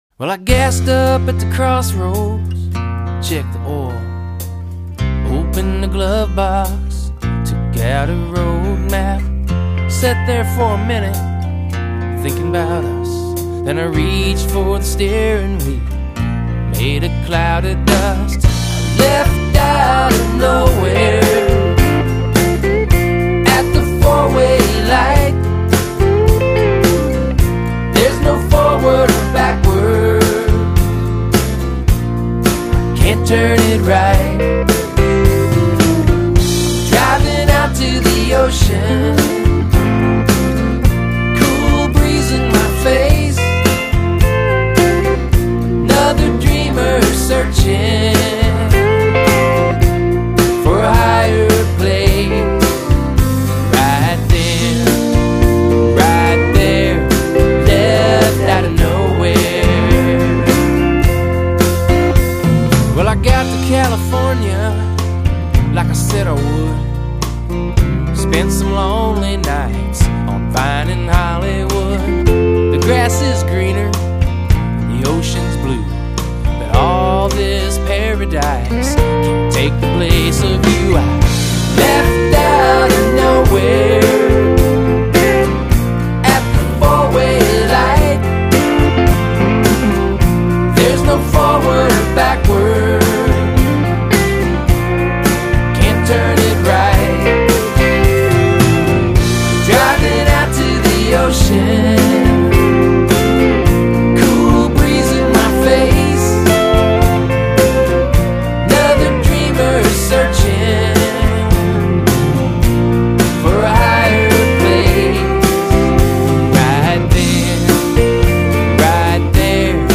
Lead vocal, acoustic guitar
Backing vocals
Bass
Drums
Percussion
Pedal steel